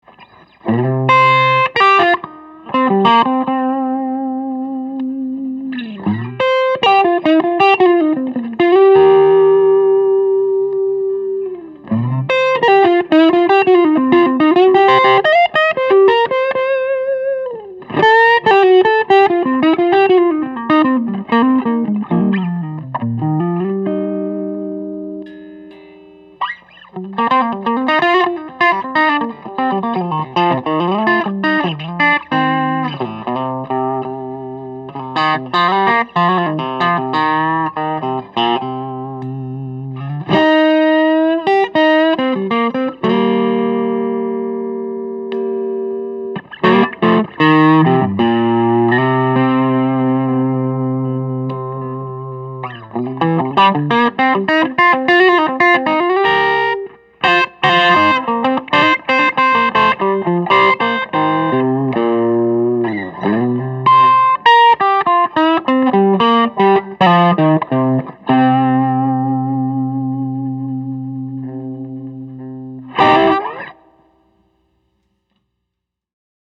Built with meticulous attention to detail, this one delivers both visual drama and sonic punch. The top is a vividly quilted Maple cap, set against a solid Mahogany back for warmth and sustain.